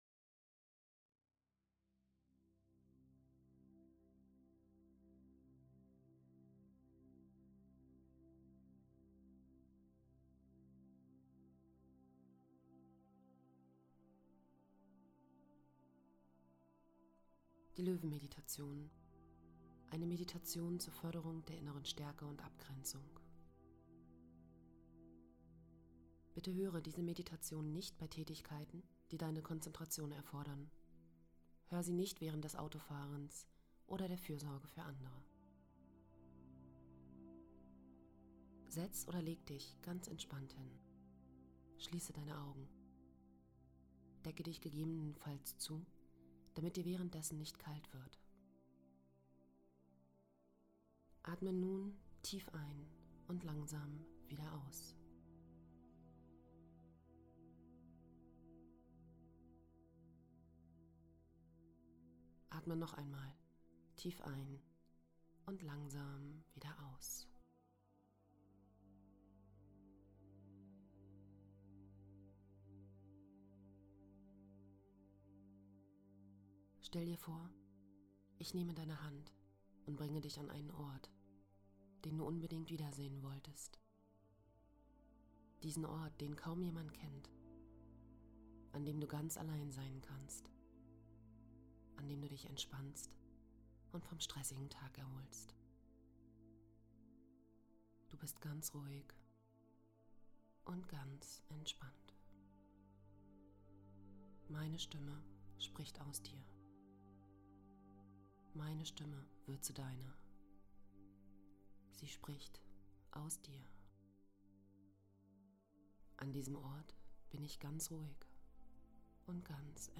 Die Löwenmeditation – Geführte Meditation zur Stärkung deiner inneren Kraft
2018_Löwenmeditation_ohneHS2.mp3